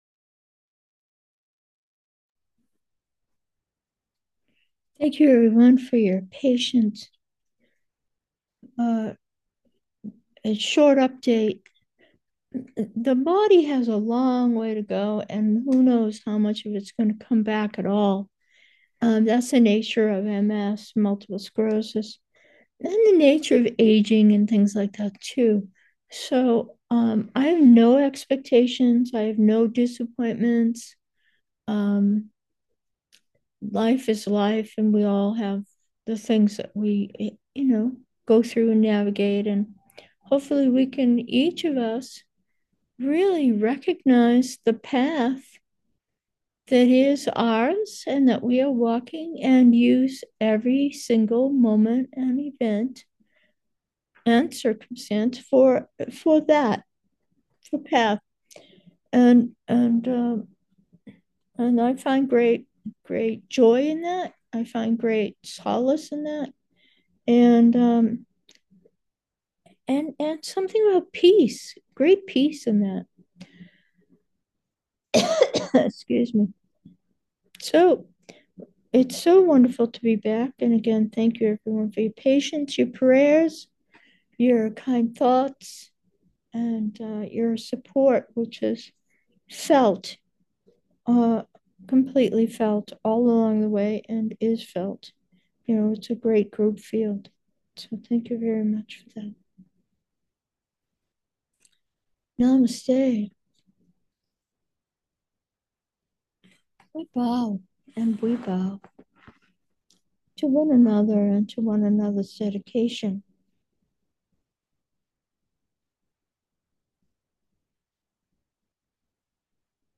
Meditation: breathe, peace follows 1